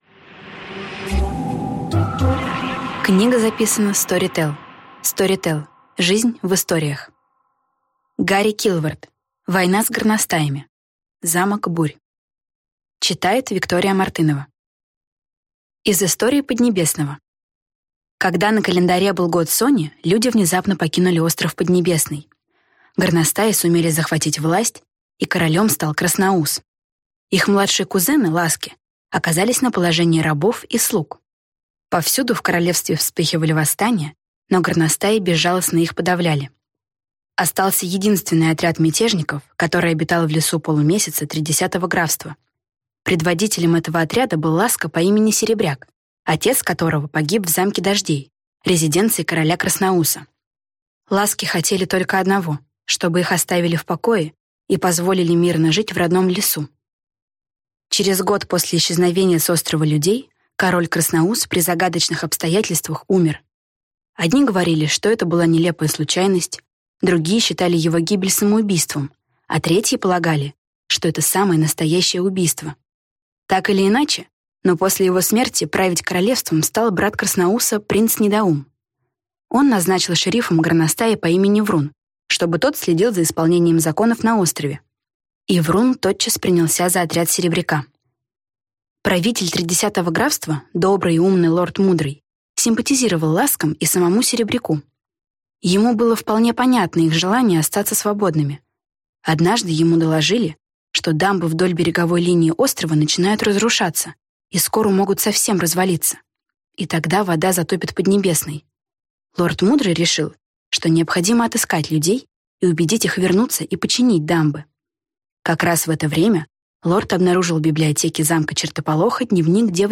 Аудиокнига Замок Бурь | Библиотека аудиокниг